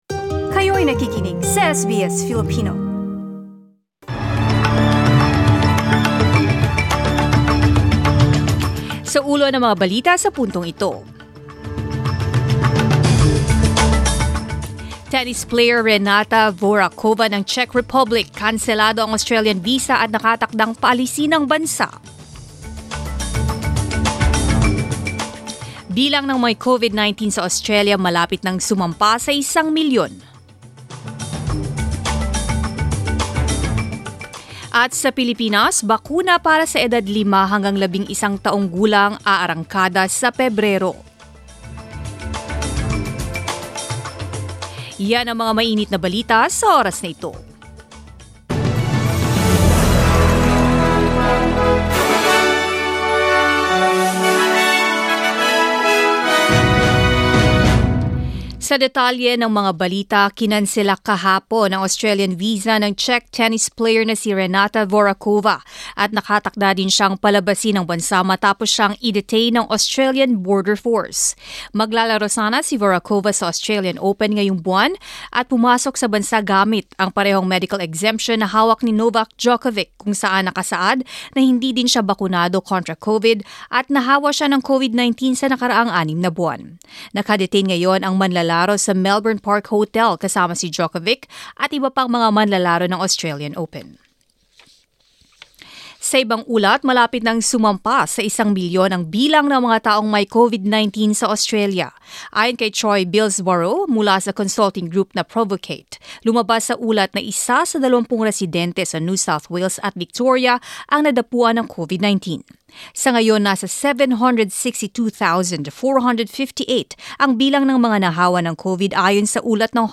Mga balita ngayong ika-8 ng Enero